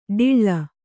♪ ḍiḷḷa